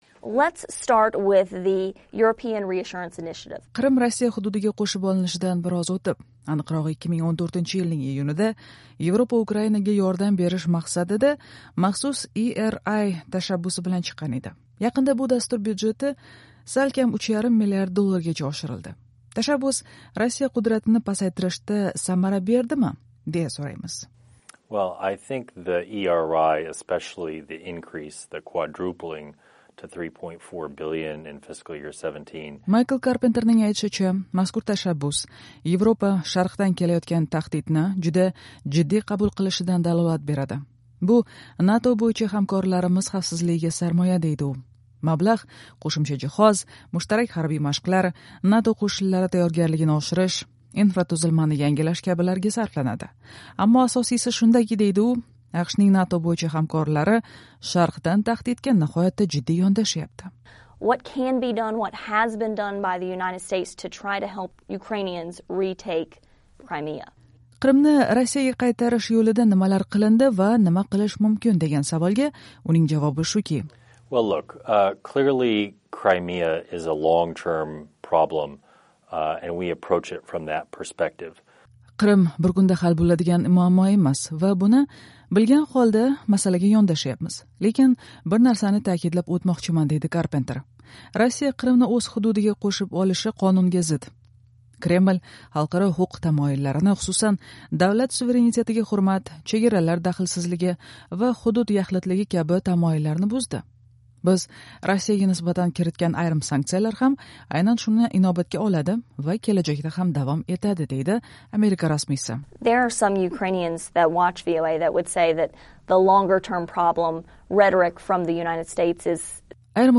“Amerika Ovozi” AQSh Mudofaa vazirligida Rossiya va Yevrosiyo uchun mas’ul Maykl Karpenter bilan suhbatlashdi.